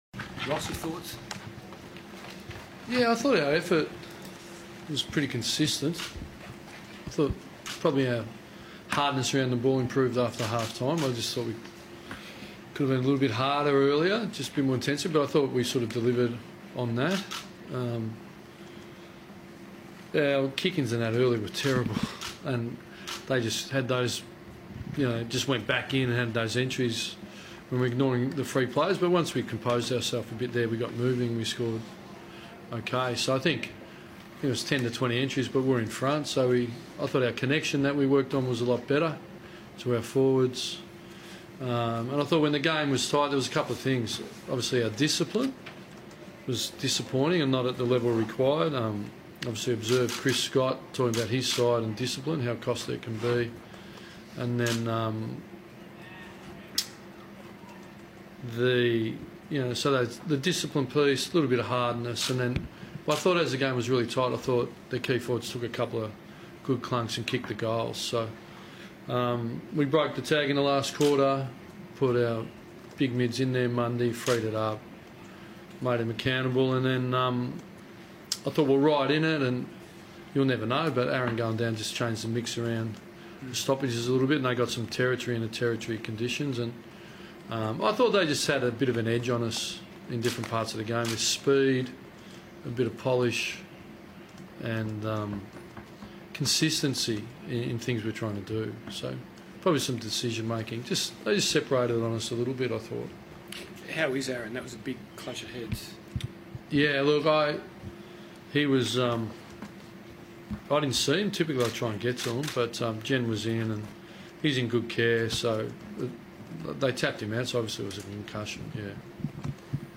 Ross Lyon spoke to the media following the loss against North Melbourne